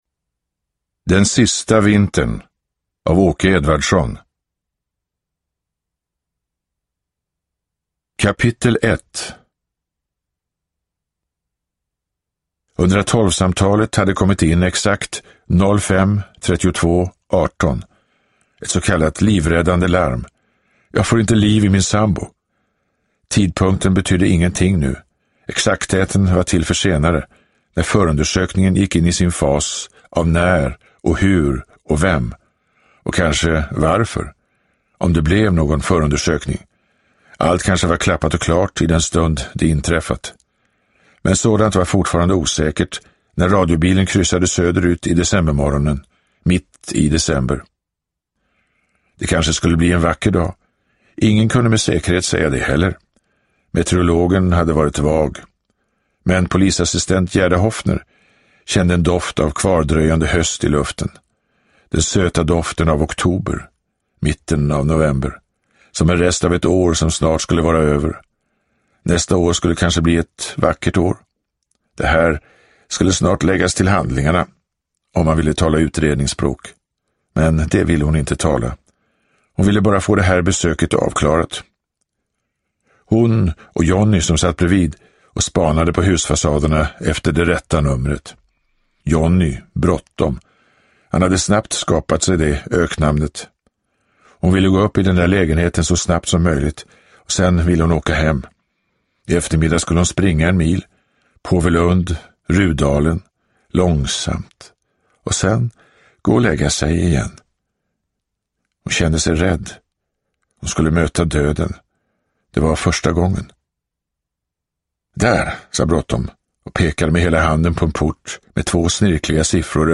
Uppläsare: Torsten Wahlund